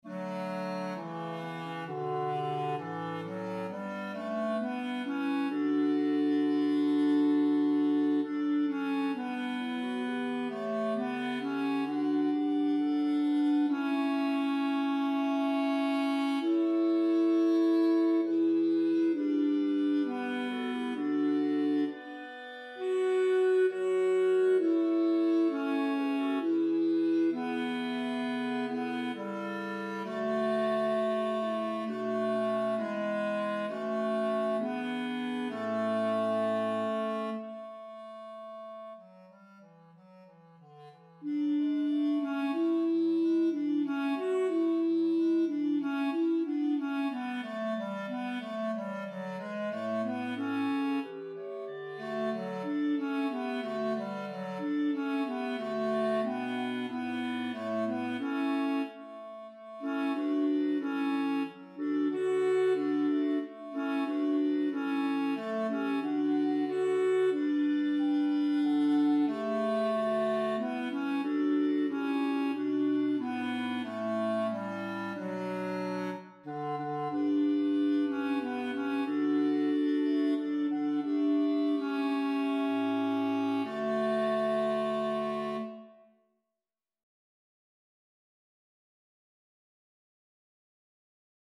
4_Sanctus_op_83_RR_Tenor.mp3